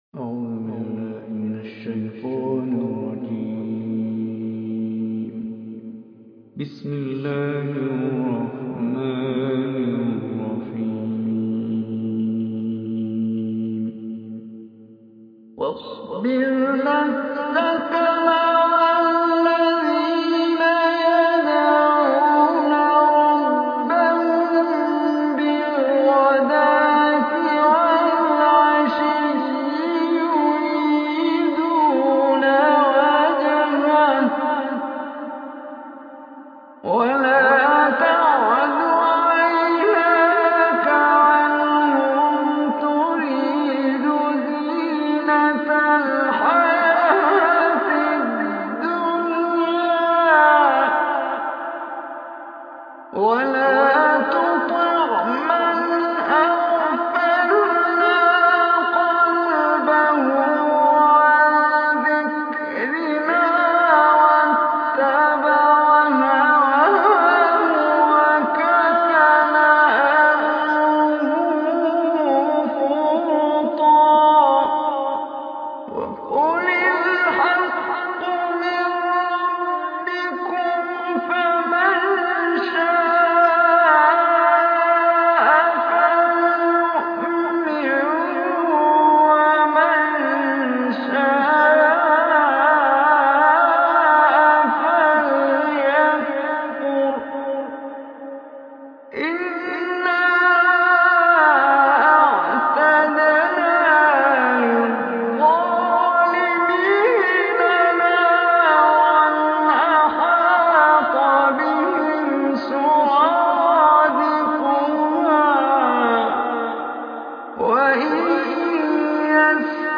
Surah Kahf MP3 Recitation by Omar Hisham
Surahj Kahf is 18 surah of Holy Quran. Listen or play online mp3 tilawat / recitation in Arabic in the voice of Omar Hisham Al Arabi.